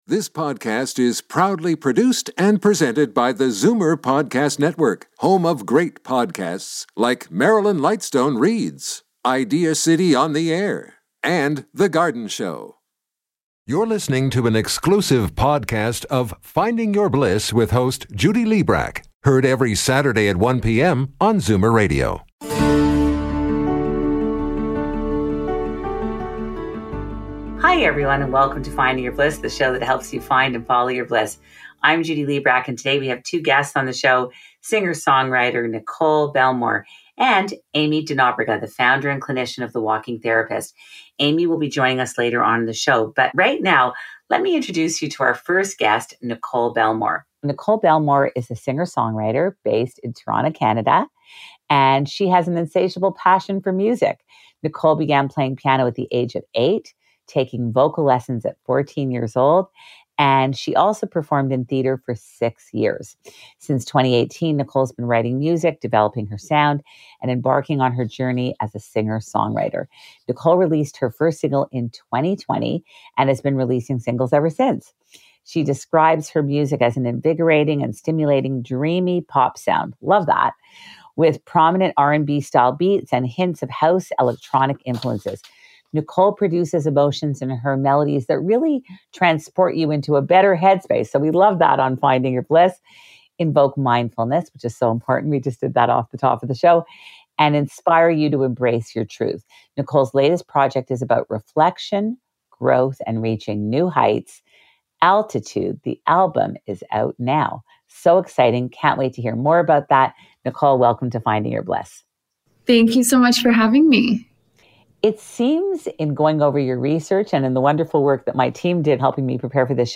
She describes her music as an invigorating and stimulating dreamy pop sound with prominent r&b-style beats and hints of house/electronic influences.